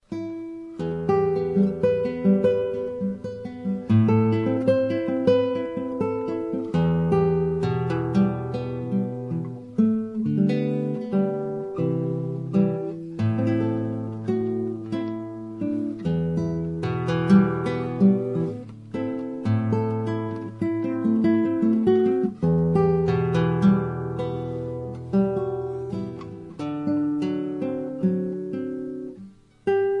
Traditional Polish Christmas Carols on classical guitar
(No Singing).